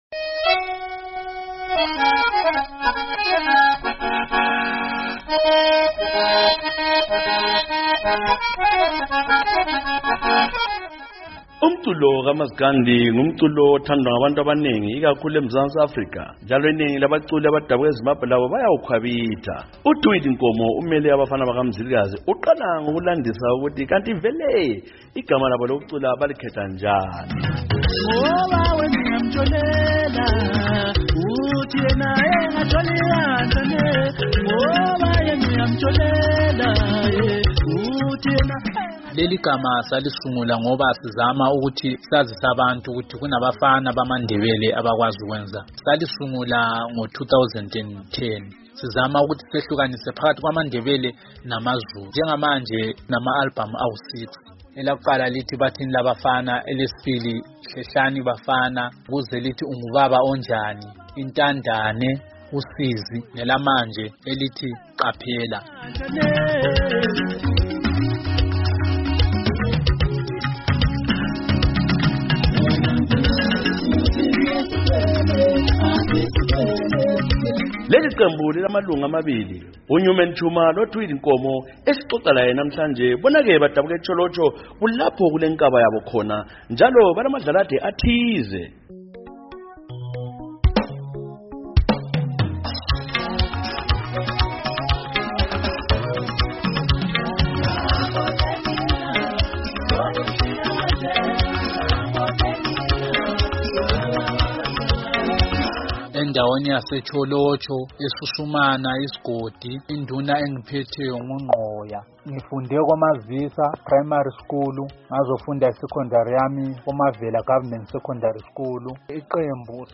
Kuhlelo 'Woza Friday' kuliviki sileqembu labadabuka eTsholotsho, eZimbabwe asebehlala kwele South Africa, abazibiza ngokuthi Abafana BakaMzilikazi, bona ke bacula ingoma zomdumo kaMasgandi.